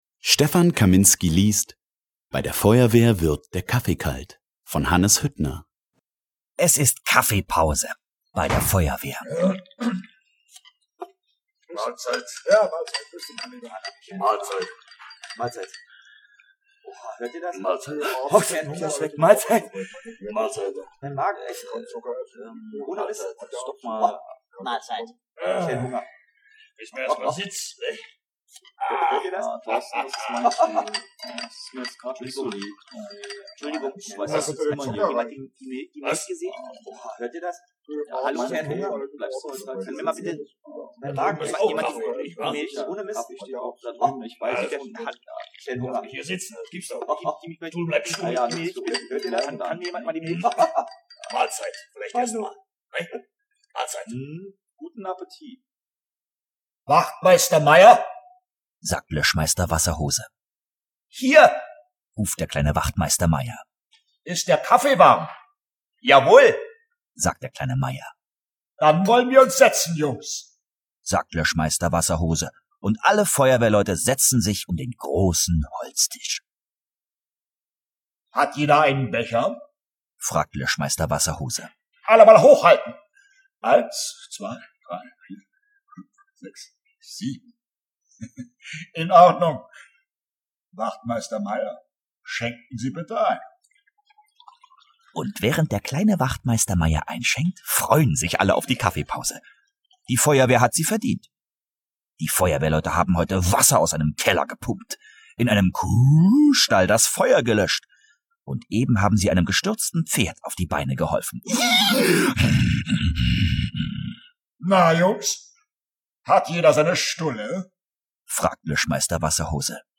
Bei der Feuerwehr wird der Kaffee kalt (DAISY Edition) Ein Feuerwehr-Hörbuch für Kinder ab 4 Jahren – ein Klassiker der Kinderliteratur Hannes Hüttner (Autor) Stefan Kaminski (Sprecher) Audio-CD 2009 | 1.